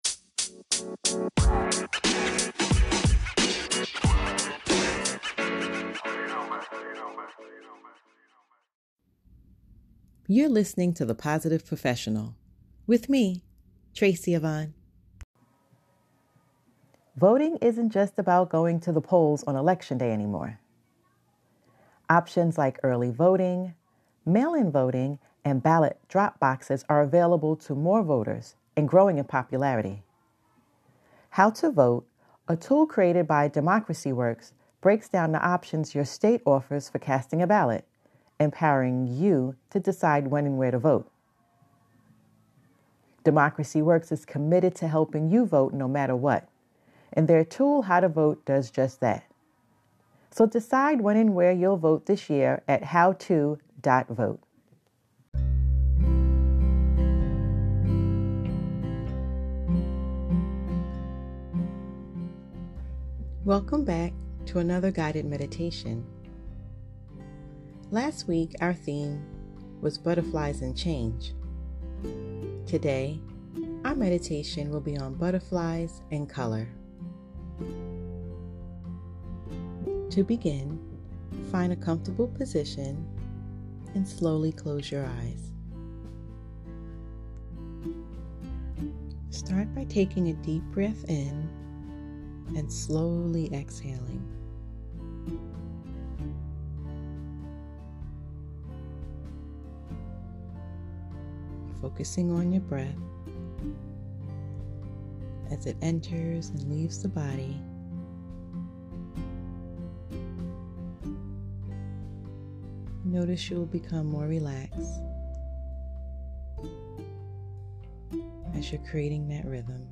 A guided mediation to help you gain a sense of peacefulness.